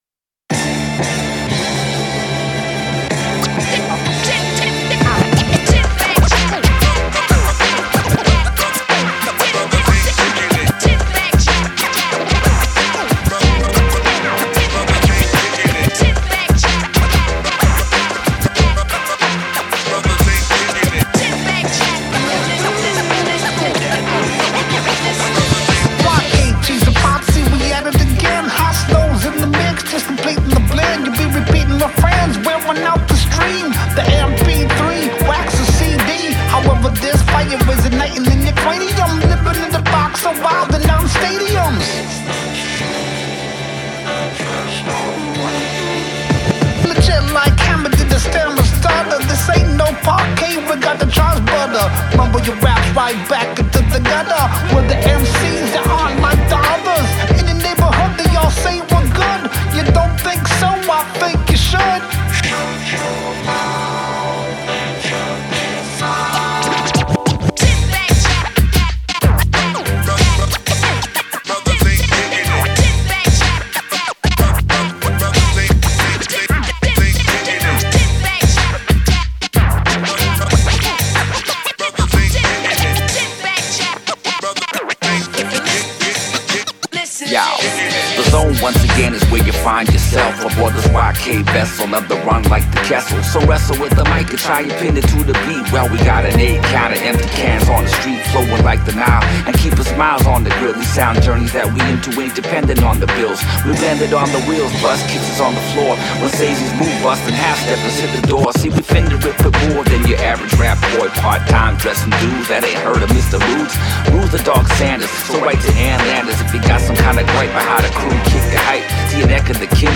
boom-bap goodness
Three tracks, zero filler, maximum head-nod.